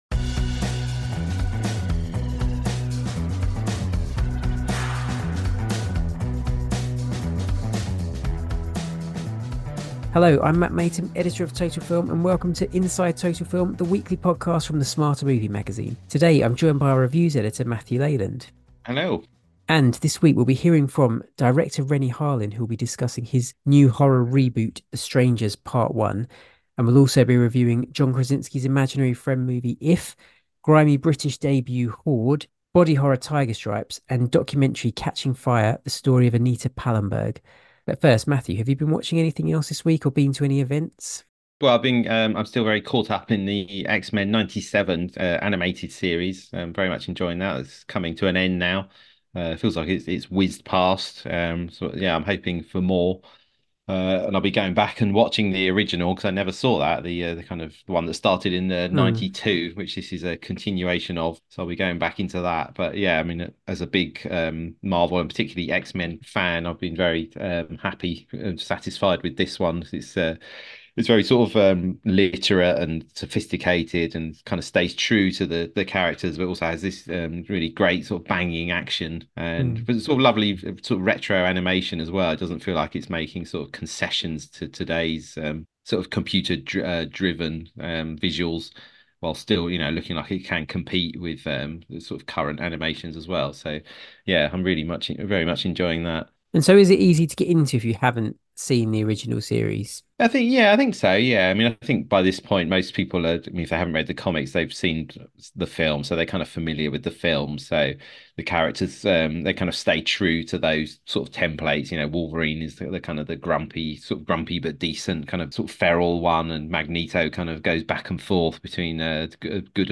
Intro and presenters discuss what they've been watching
Inteview with The Strangers: Chapter 1 director Renny Harlin